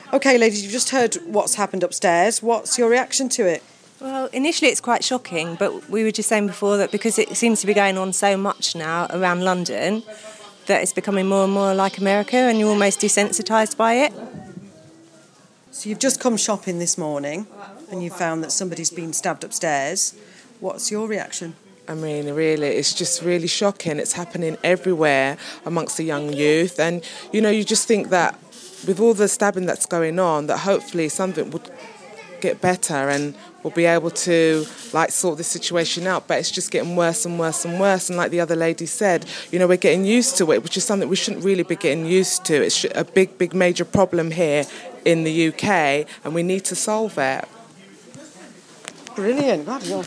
Reaction to the fatal stabbing in The Mall Wood Green from shoppers.